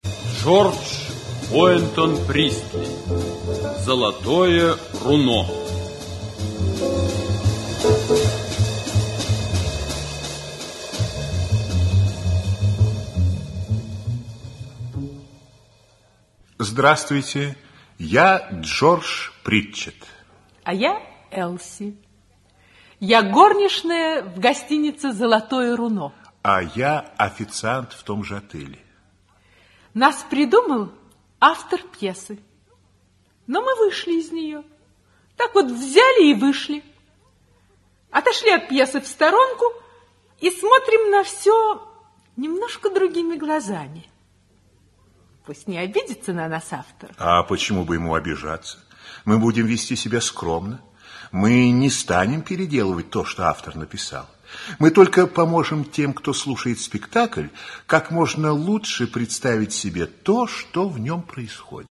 Аудиокнига Золотое руно (спектакль) | Библиотека аудиокниг
Aудиокнига Золотое руно (спектакль) Автор Джон Пристли Читает аудиокнигу Актерский коллектив.